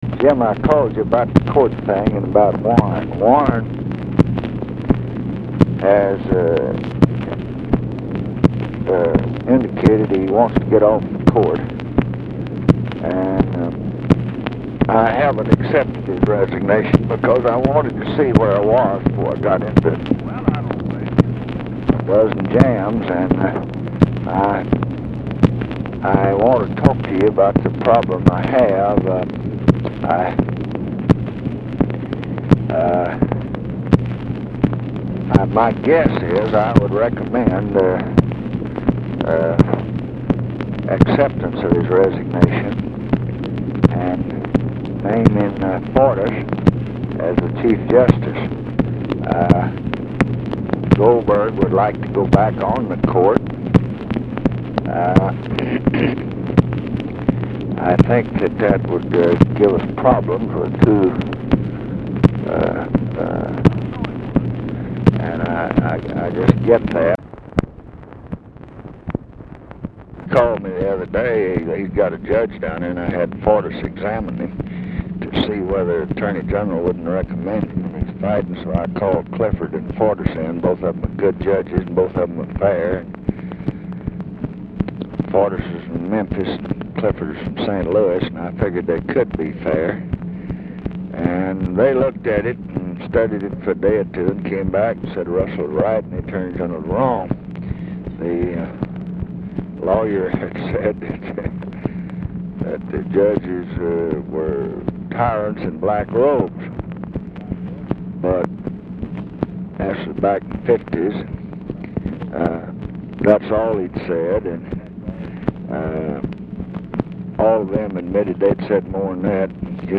Excerpts from a 33-minute conversation in which President Johnson–unsuccessfully, as it turned out, attempted to employ a version of the Johnson Treatment on Jim Eastland, in the run-up to the Fortas/Thornberry nominations.